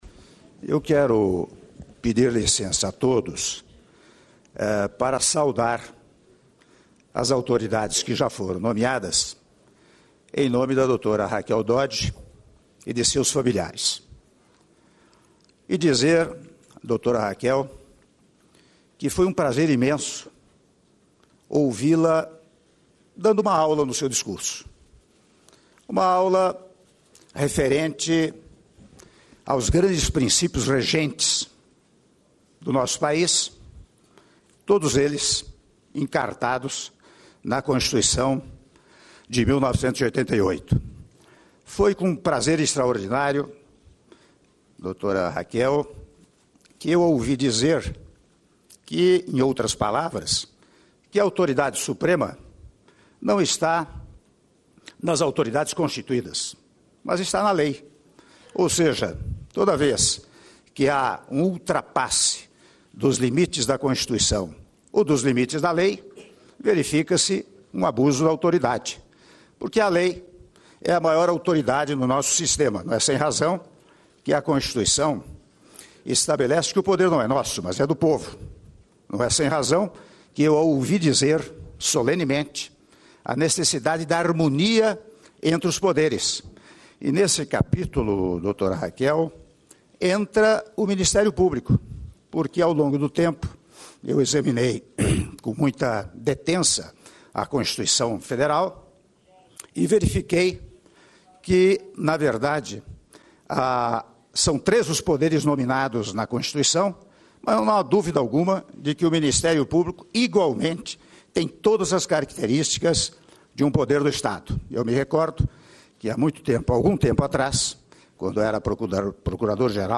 Áudio do discurso do Presidente da República, Michel Temer, na cerimônia de posse da Procuradora-Geral da República, Raquel Dodge - Brasília/DF- (05min54s)